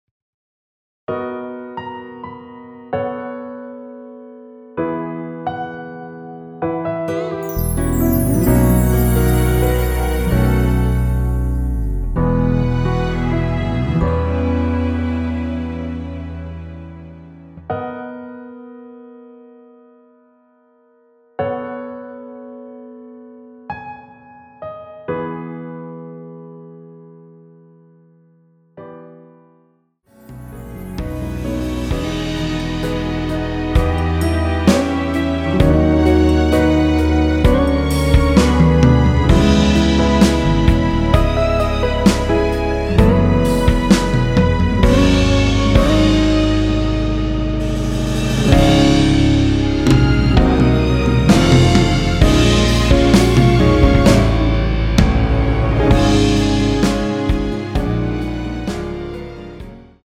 원키에서(-3)내린 MR입니다.(미리듣기 확인)
앞부분30초, 뒷부분30초씩 편집해서 올려 드리고 있습니다.
중간에 음이 끈어지고 다시 나오는 이유는